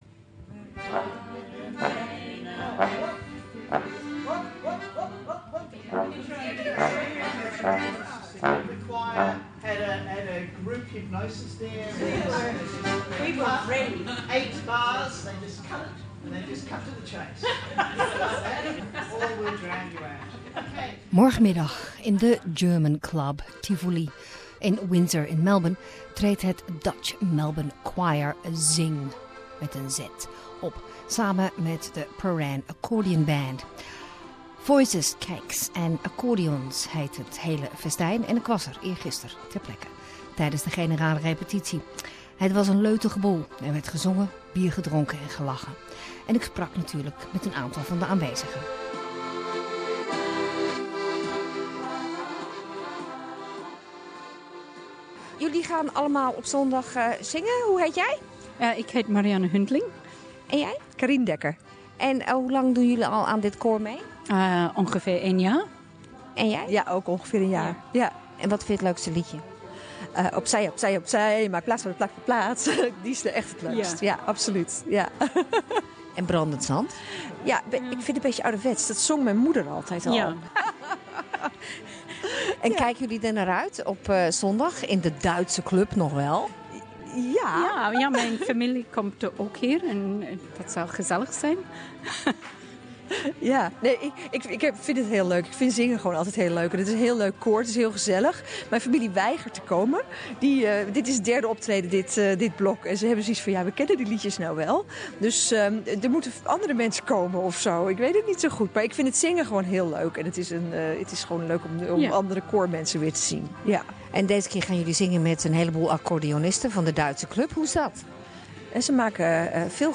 We attended the general rehearsal of 'Voices, Cakes and Accordions', 'Zing', the Dutch Choir Melbourne is performing with the Prahran Accordean Band in the German Club Tivoli. A story about singing, beer, swaying with the music, and more beer...